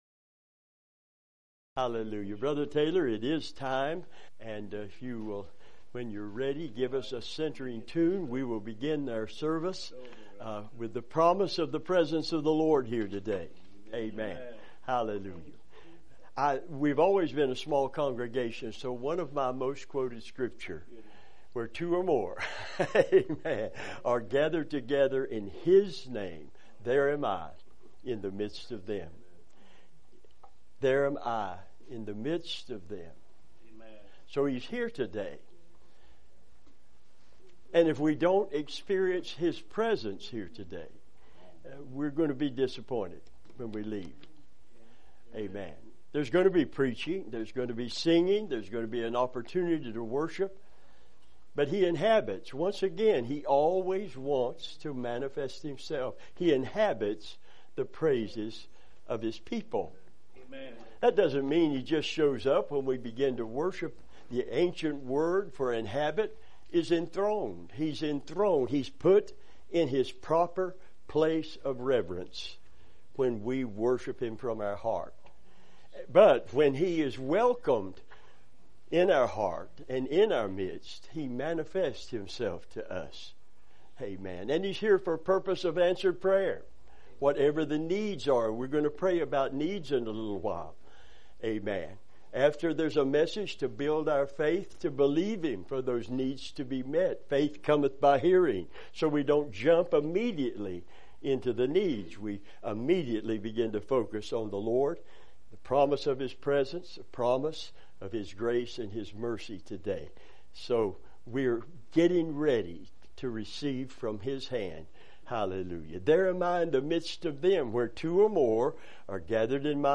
Revival Sermons